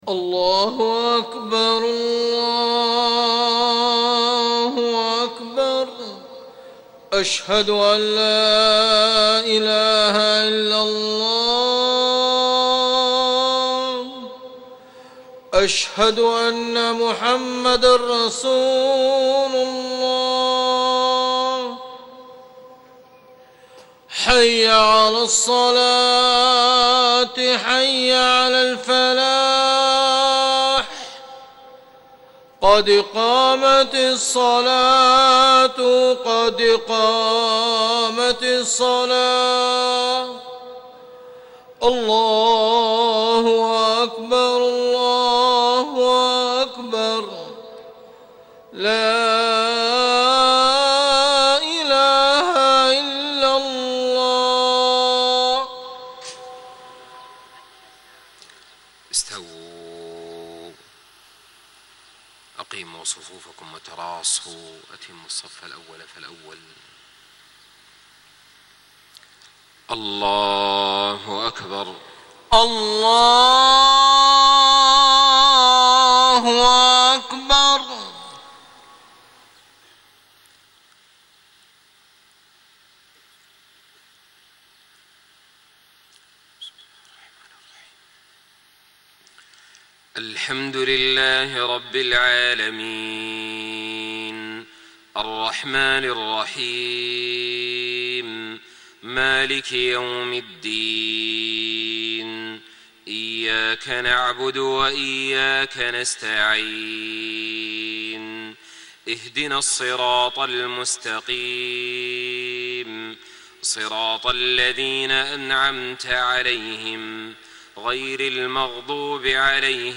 صلاة الفجر 3-7-1434هـ من سورة الكهف > 1434 🕋 > الفروض - تلاوات الحرمين